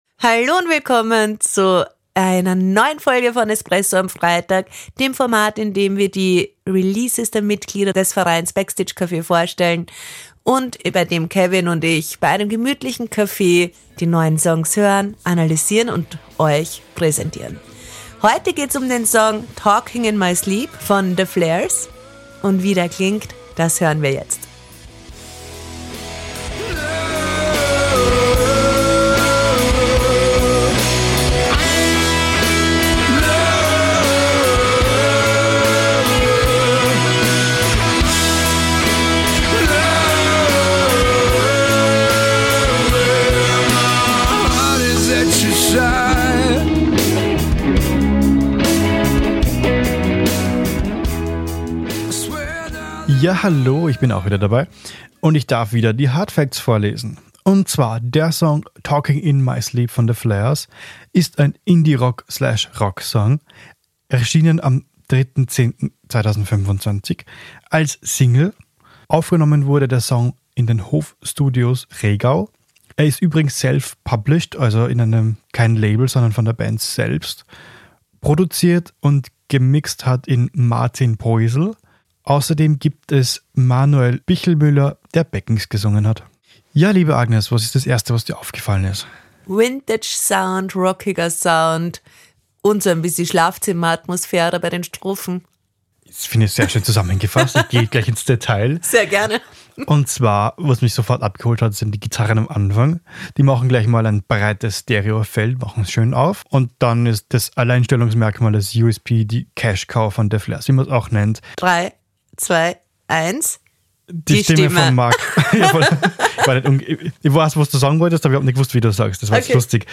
Die Release-Rezension für Newcomer